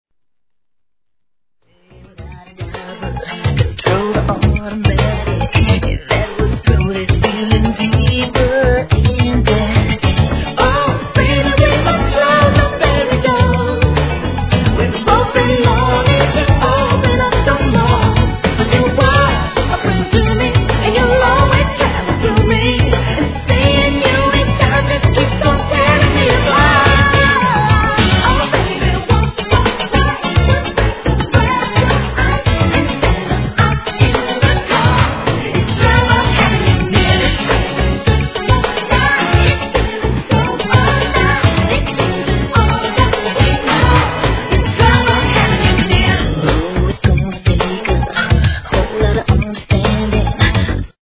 HIP HOP/R&B
グランドビート調のトラック上できらびやかに歌う彼女のヴォーカルが映えた一曲！